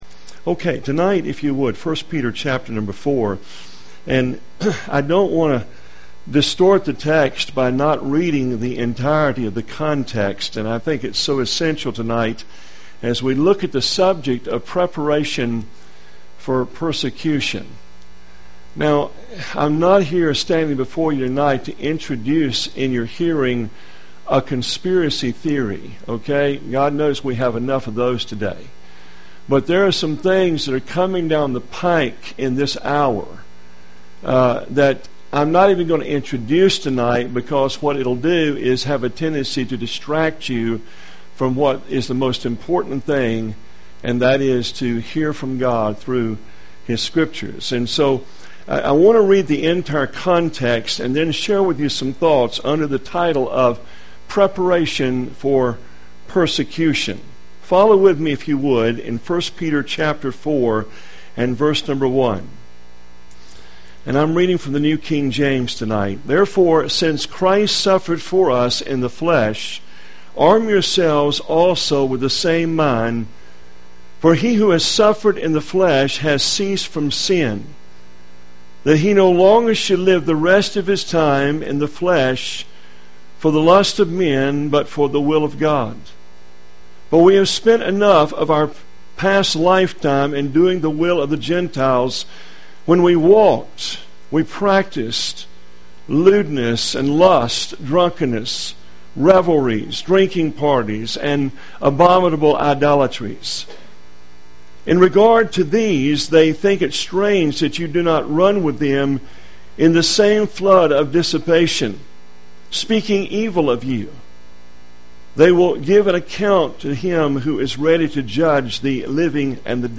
In this sermon, the preacher discusses the presence of Christ in the midst of suffering. He shares a story from Francis Chan's book, Crazy Love, about South Koreans who went to minister in Afghanistan and were taken captive by the Taliban.